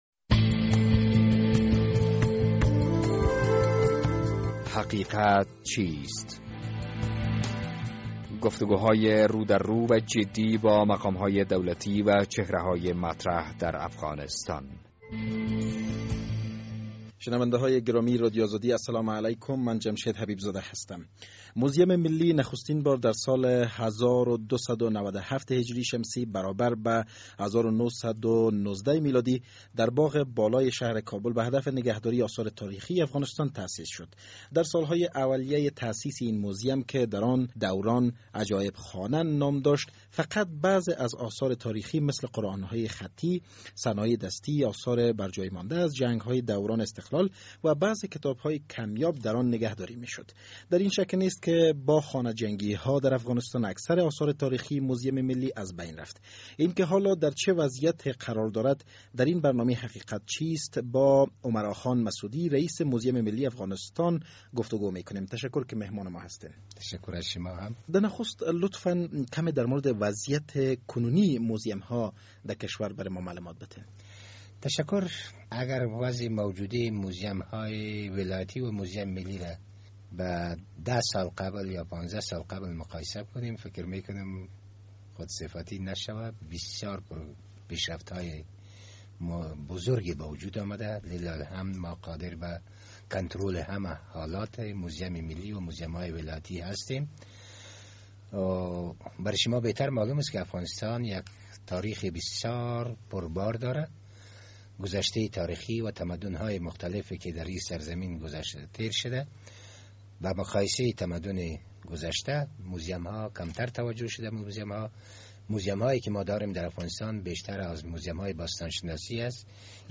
در این برنامه حقیقت چیست با عمراخان مسعودی رییس عمومی موزیم ملی افغانستان گفتگو کرده ایم...